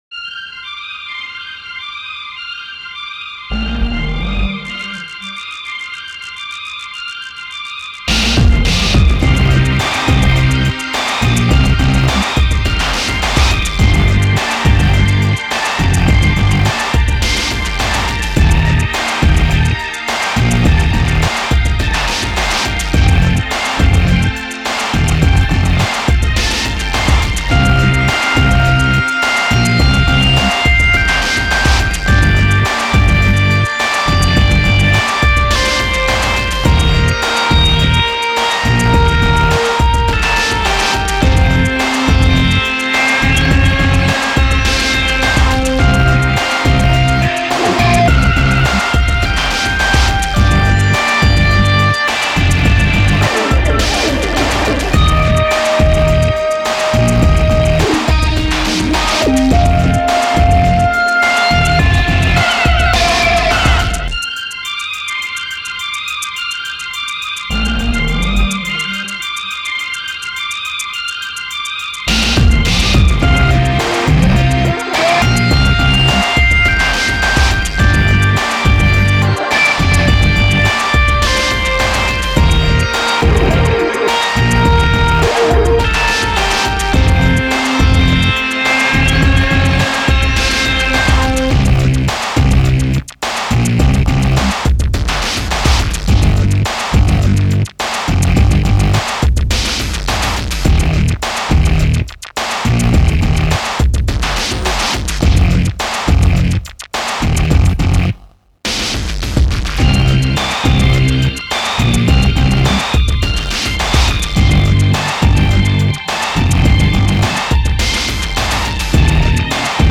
MID-TEMPO POP ROCK HIPHOP DIRTY DRIVING ELECTRONIC DRUMS